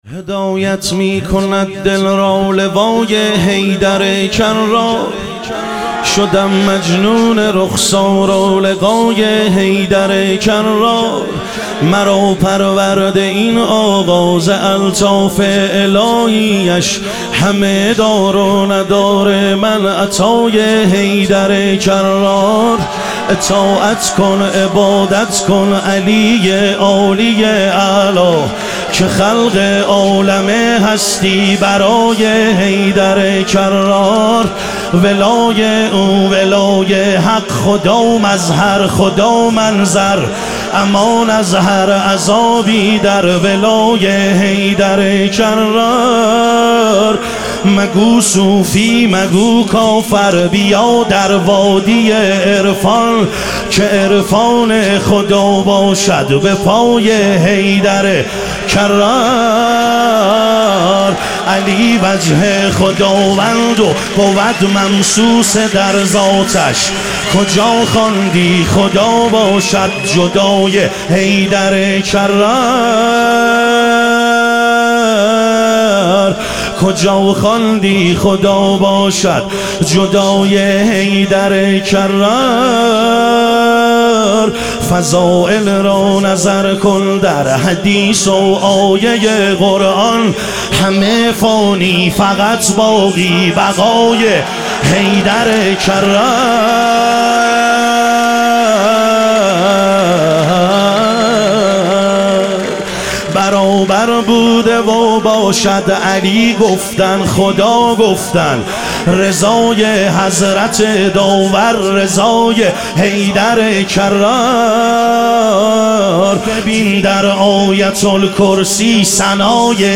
ظهور وجود مقدس حضرت مهدی علیه السلام - مدح و رجز